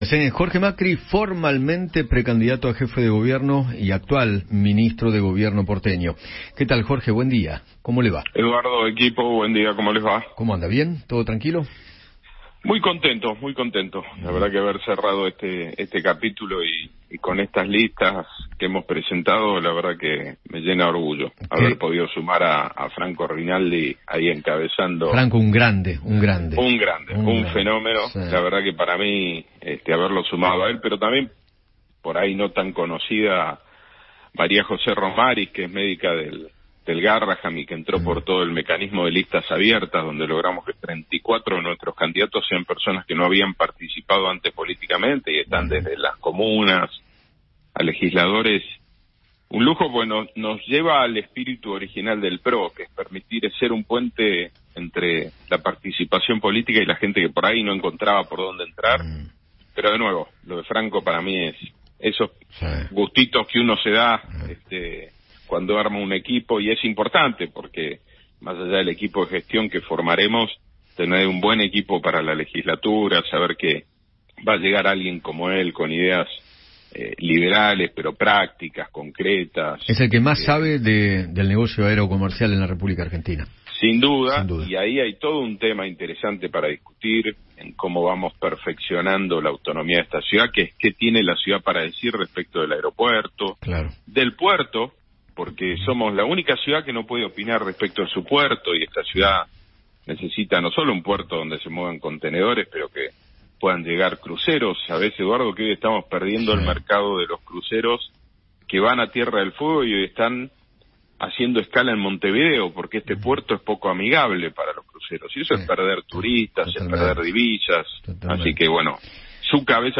Jorge Macri, precandidato a Jefe de Gobierno porteño, conversó con Eduardo Feinmann sobre la interna electoral de Juntos por el Cambio.